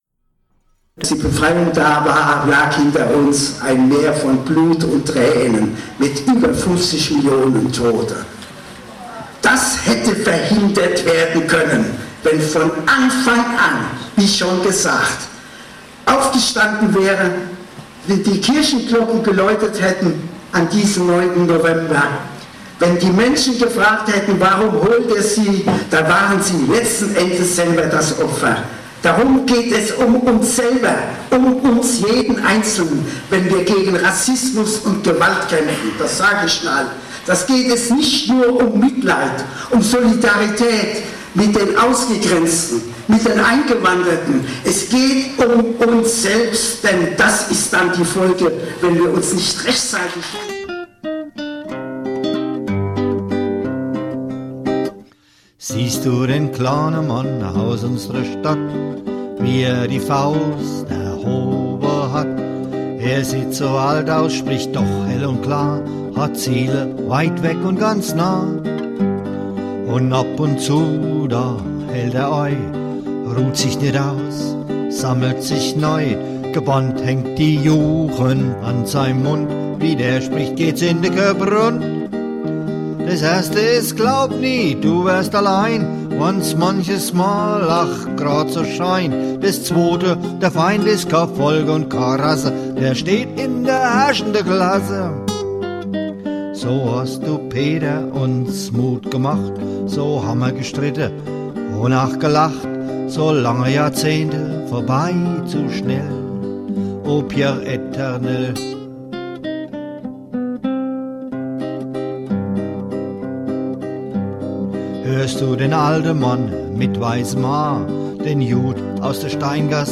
eingerahmt in mahnende O-Töne